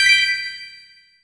ButtonPress.wav